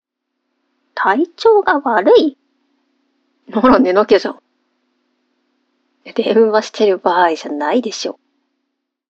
やさしい声は、いちばん効くおくすり💊
ボイスサンプル